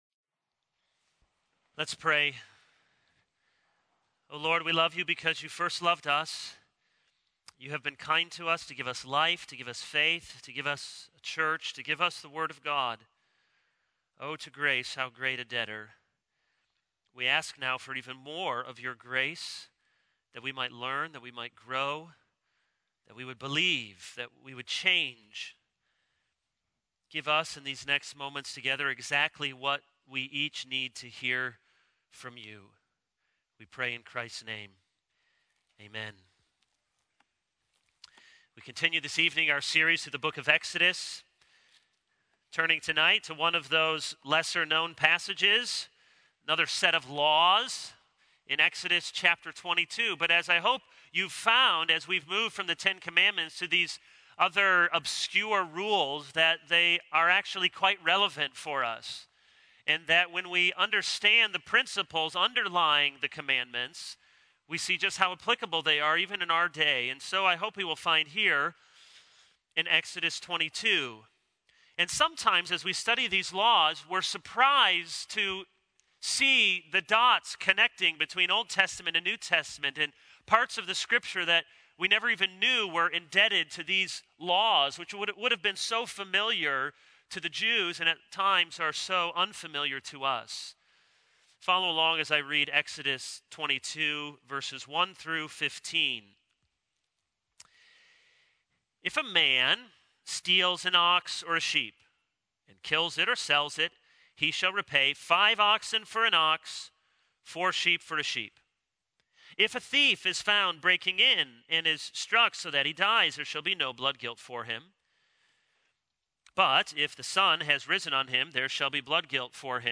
This is a sermon on Exodus 22:1-15.